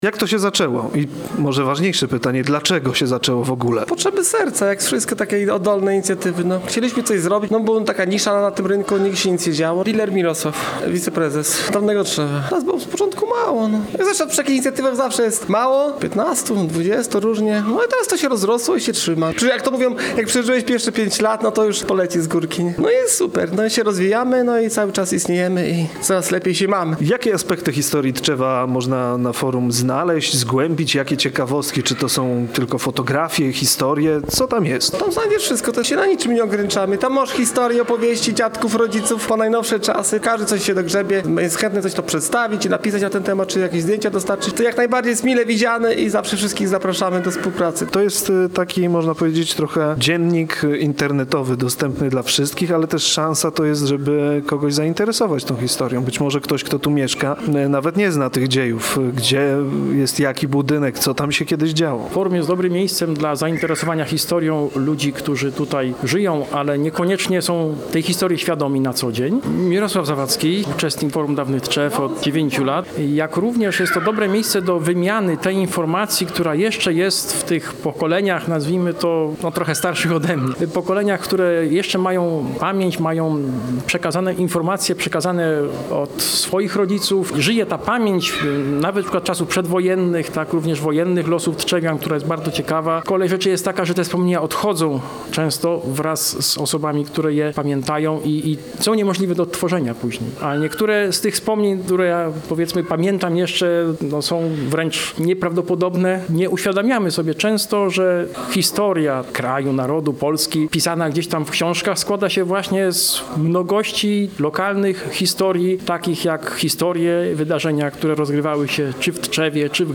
Oprócz tego można także posłuchać rozmowy: